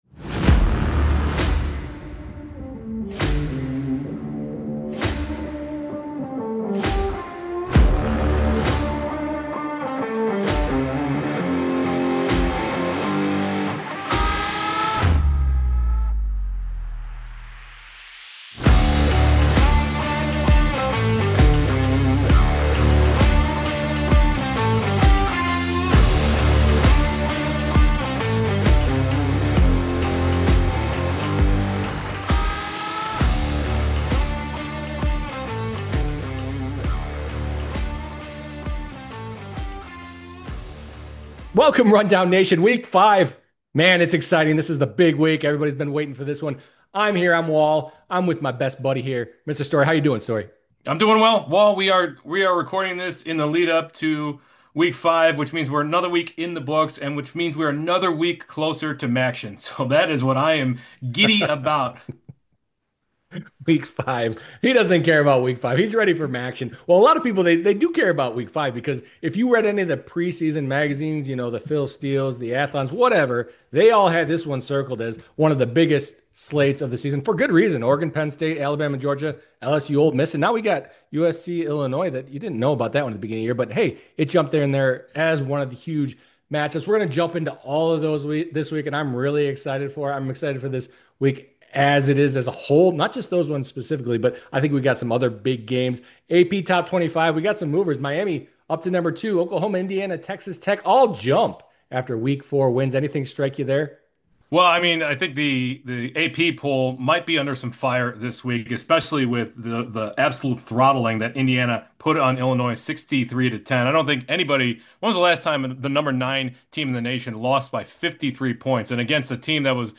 Hades II Hype, Teens Buying Games, & Couch-Co-Op Headaches | New Dad Gaming Ep 372 41:38 Play Pause 1d ago 41:38 Play Pause Play later Play later Lists Like Liked 41:38 We did it: our once-a-year in-person episode!